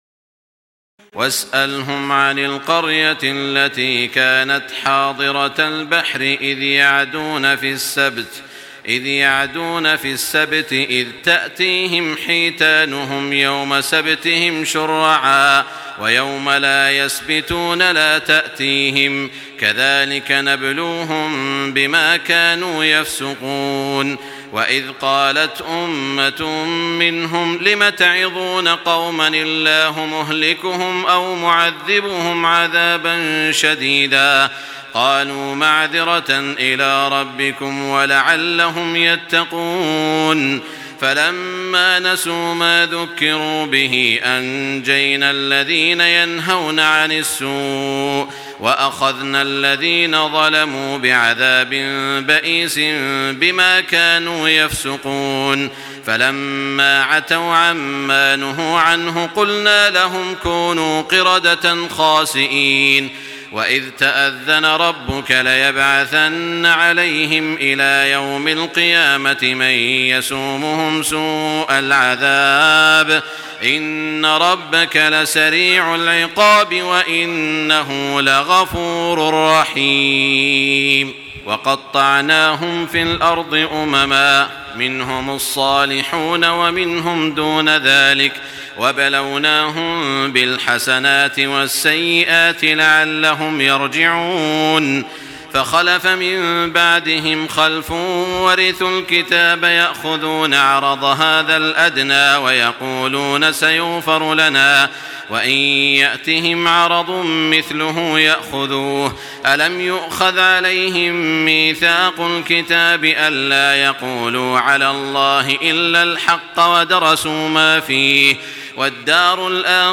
تراويح الليلة الثامنة رمضان 1425هـ من سورتي الأعراف (163-206) والأنفال (1-40) Taraweeh 8 st night Ramadan 1425H from Surah Al-A’raf and Al-Anfal > تراويح الحرم المكي عام 1425 🕋 > التراويح - تلاوات الحرمين